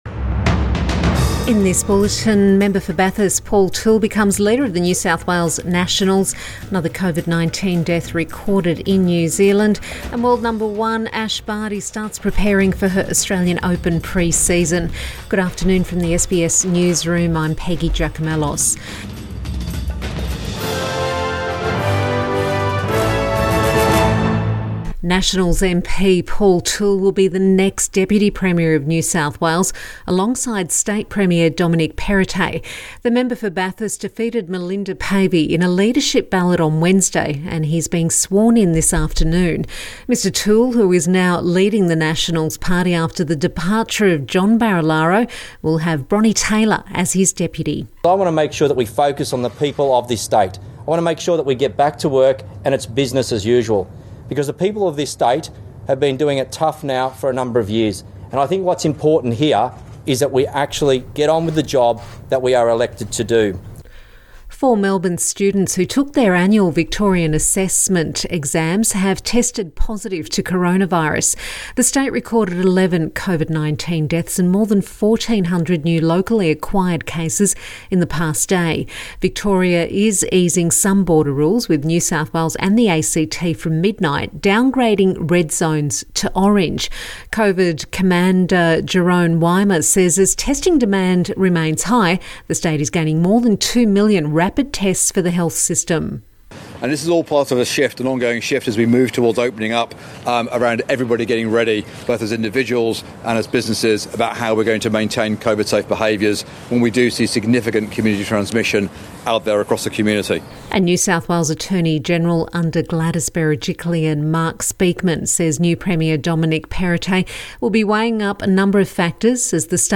PM bulletin October 6 2021